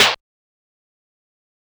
SNARE - ROBO COP.wav